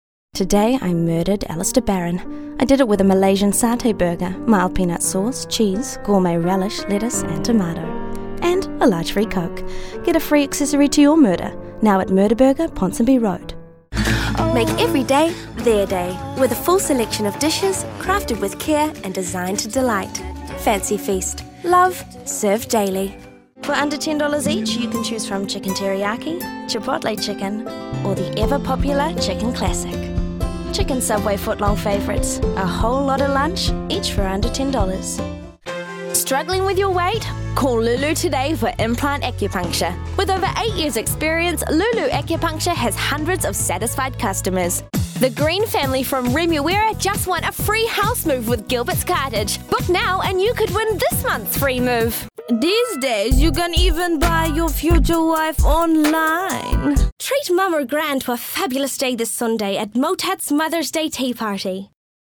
Demo
Teenager, Young Adult, Adult
new zealand | natural
comedy
warm/friendly